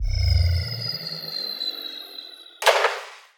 land.wav